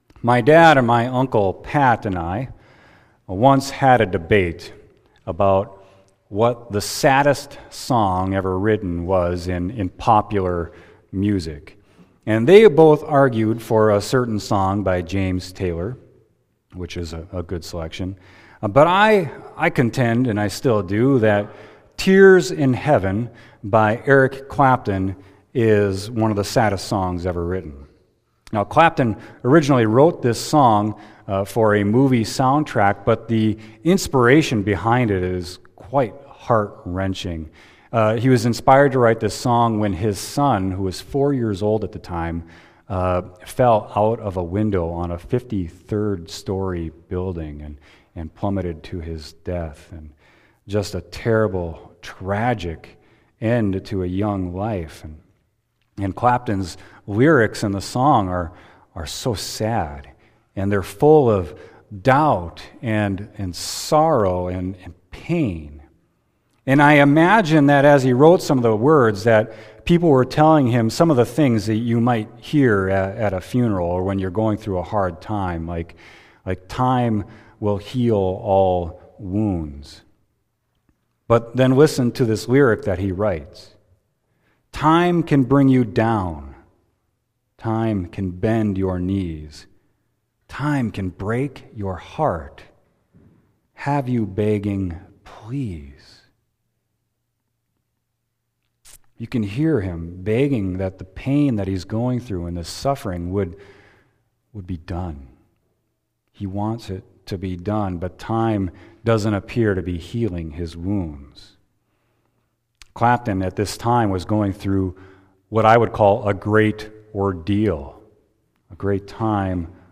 Sermon: Revelation 7.9-17